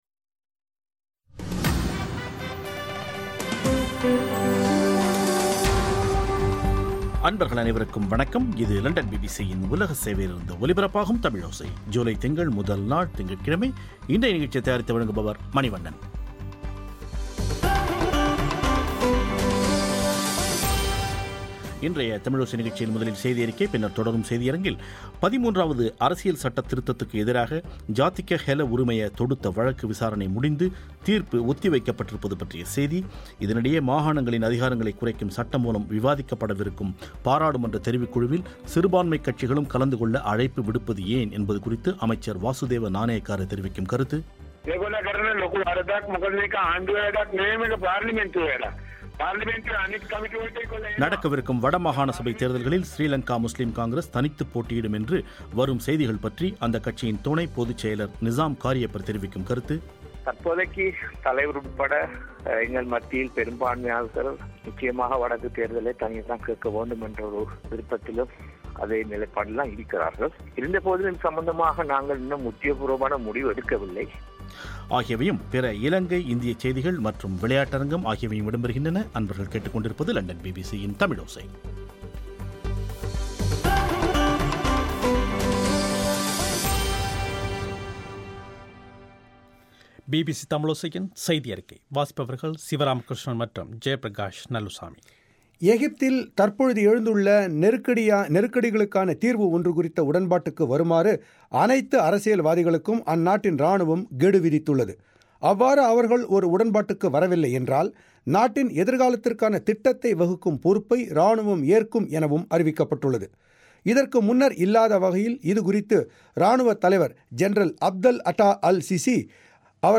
மாகாணங்களின் அதிகாரங்களைக் குறைக்கும் சட்டமூலம் விவாதிக்கப்படவிருக்கும் பாராளுமன்ற தெரிவுக்குழுவில் சிறுபான்மைக் கட்சிகளும் கலந்துகொள்ள அழைப்பு விடுப்பது ஏன் என்பது குறித்து அமைச்சர் வாசுதேவ நானயக்காரவுடன் செவ்வி